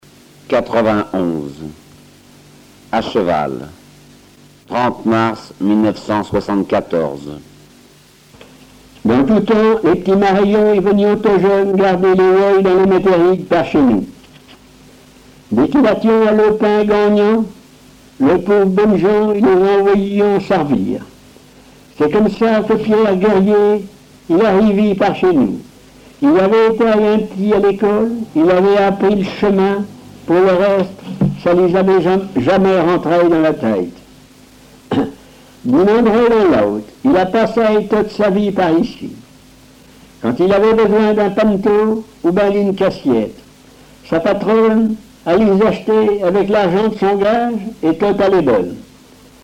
Mémoires et Patrimoines vivants - RaddO est une base de données d'archives iconographiques et sonores.
Genre récit
Récits en patois